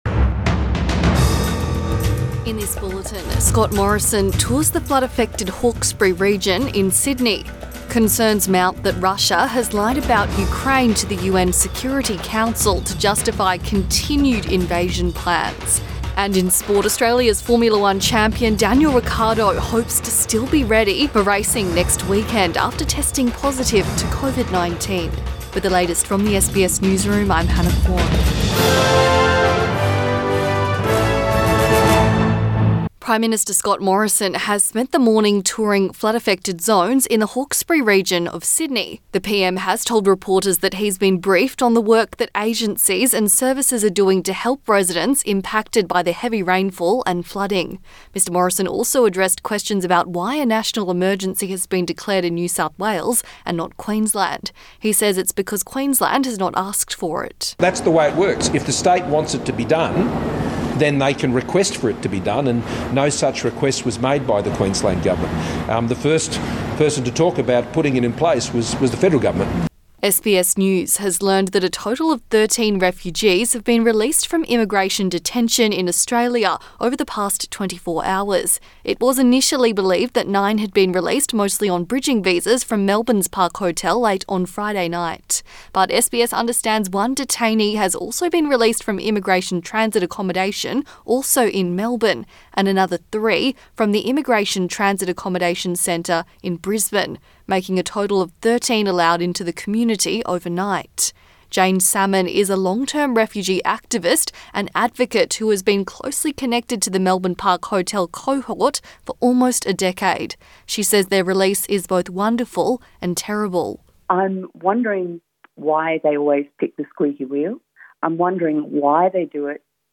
Midday bulletin 12 March 2022